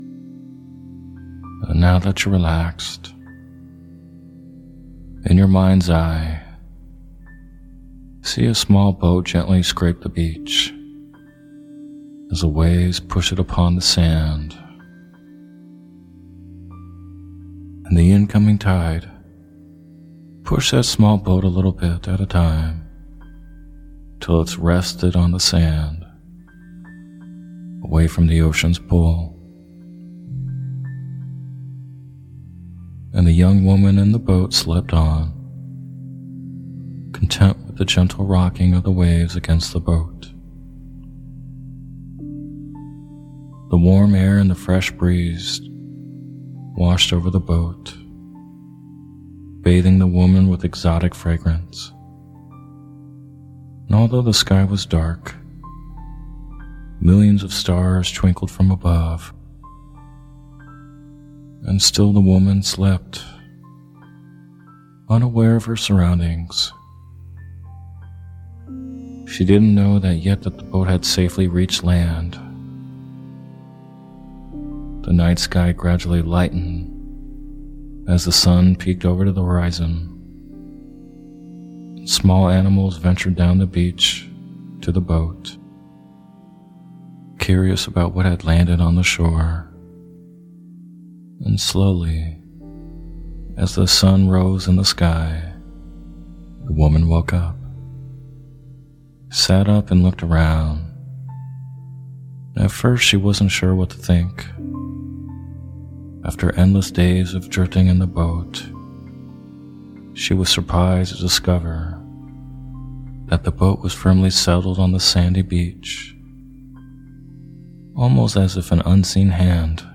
Story Based Meditation “The Tropical Forest”
In this story based meditation, you’ll be guided via a story about traveling through a Tropical Forest for the purpose of releasing blocks and barriers towards a goal you have in mind.